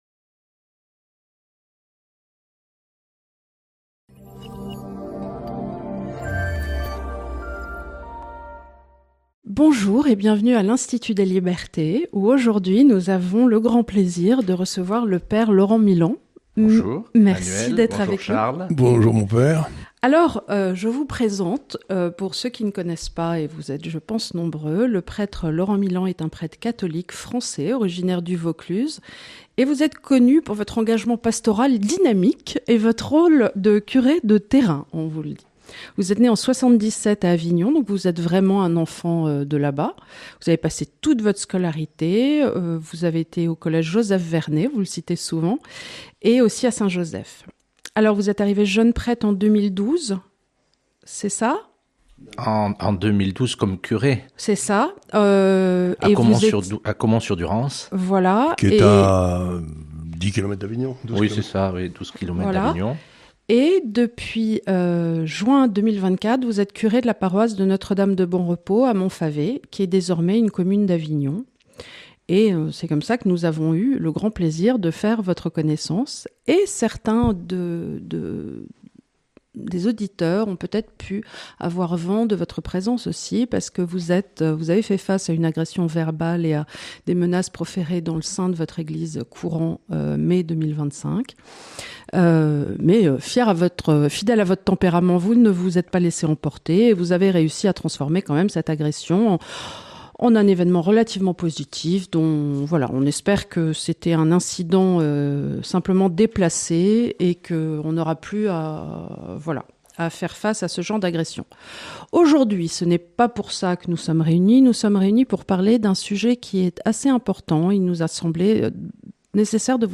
Dans cet entretien hors du temps politique